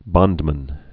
(bŏndmən)